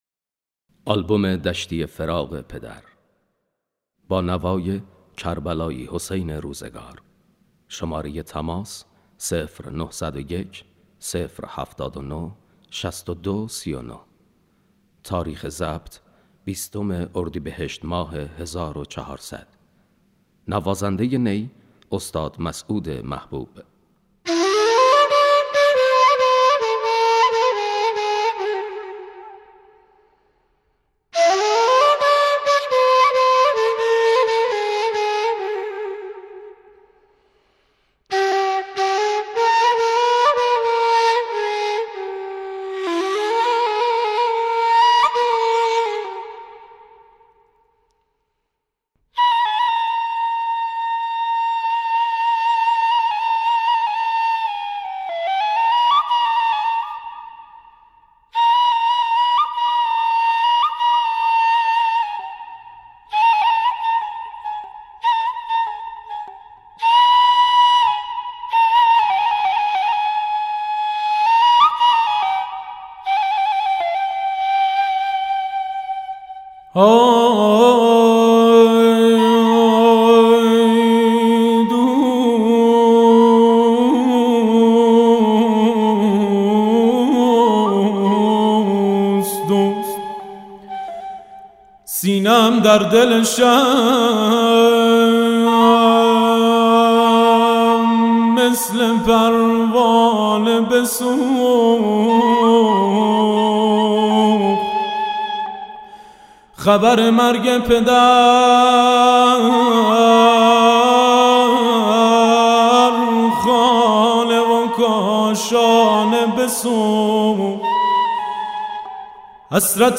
آواز ، دشتی غمناک ، دشتی سوزناک